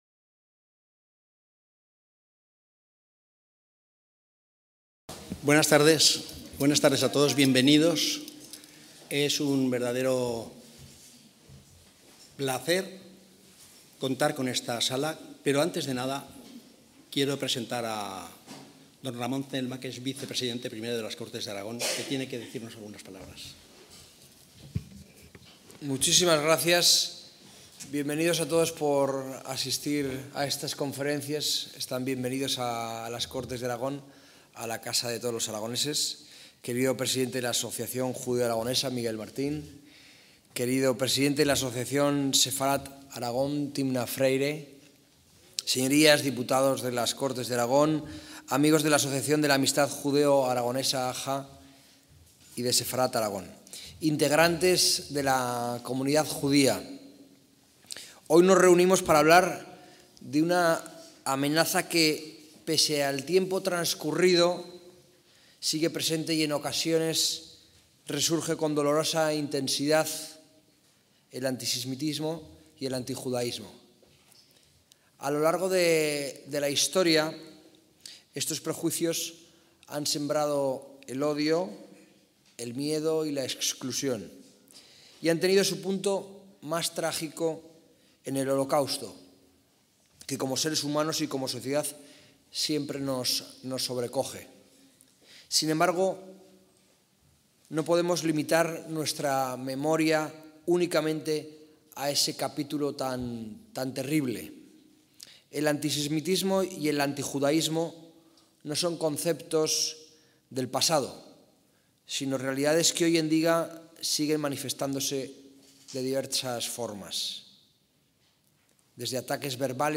ACTOS EN DIRECTO - Los pasados 23 y 24 de octubre de 2024 tuvieron lugar en el Palacio de Alfajería de Zaragoza, por iniciativa y organización de Amistad Judeo-Aragonesa y Sefarad-Aragón sendos encuentros con ponencias, cuyo tema central es el auge del antisemitismo y la judeofobia durante este año.